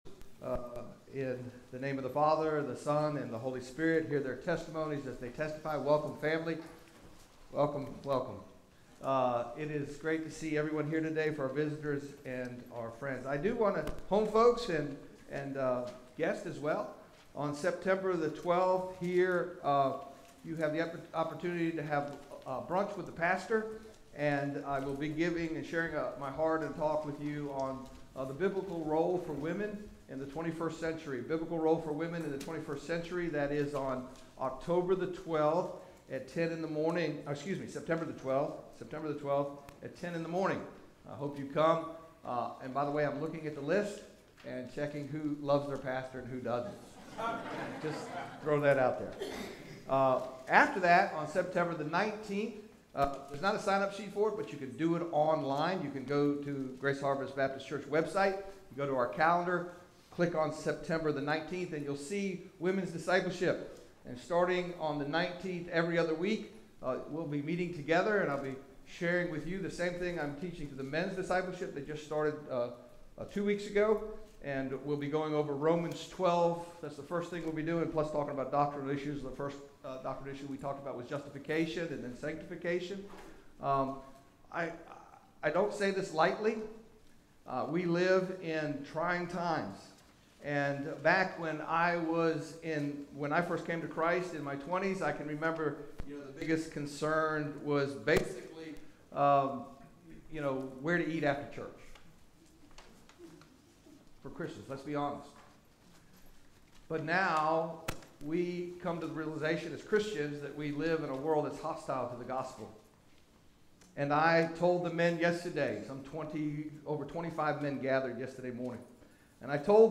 Greatest Sermon Ever Preached; What Now?
GHBC-Late-Service-8-16-20.mp3